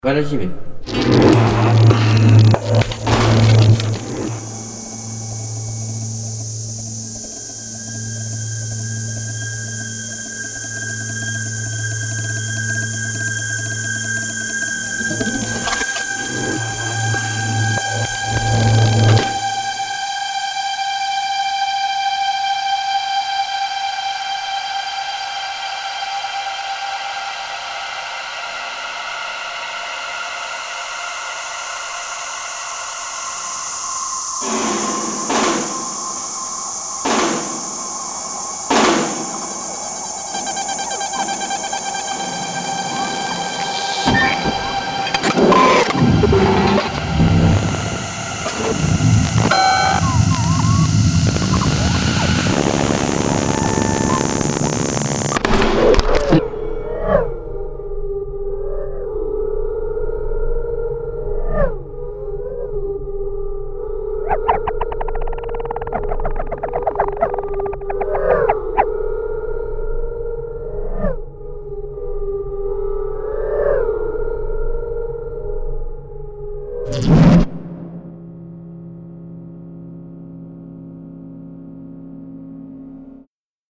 sound objects